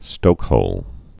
(stōkhōl)